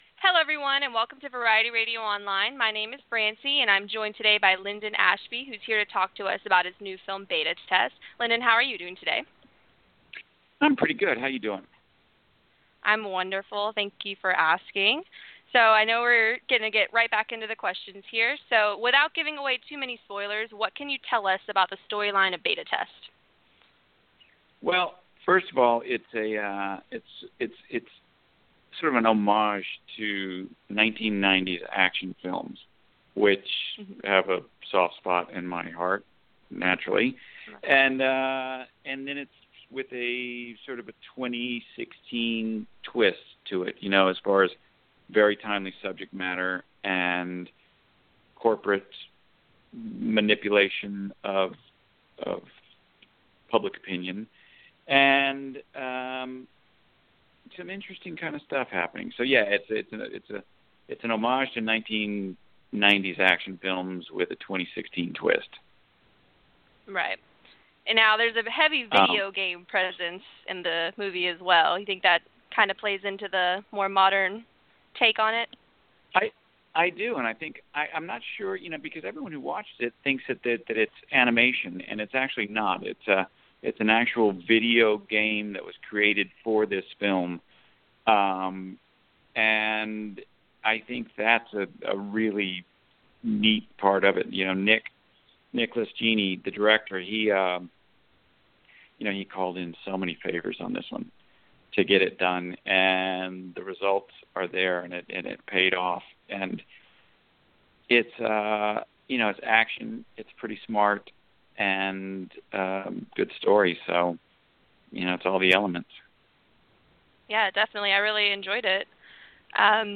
Linden Ashby Interview